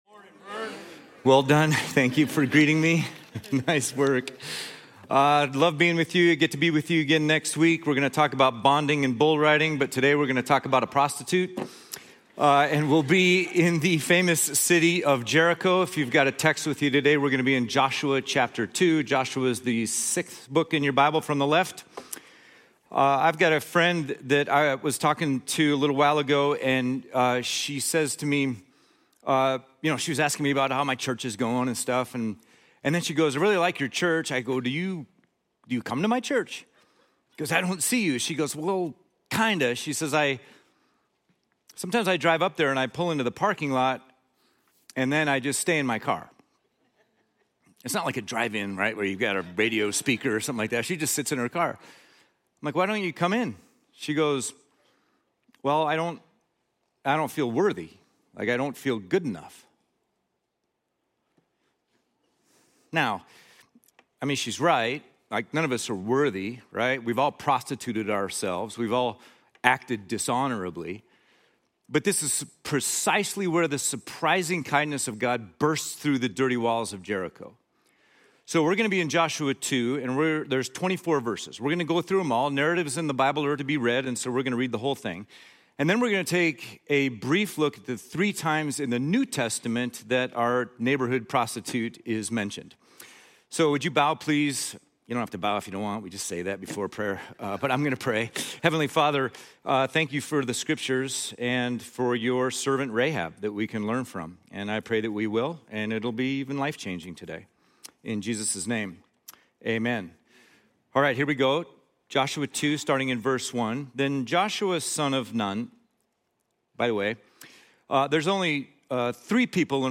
Guest Speaker&nbsp